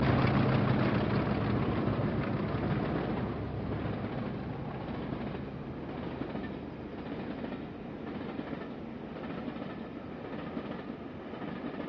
40.184 heads 2030 Stirling-Dover Motorail train through Cumbernauld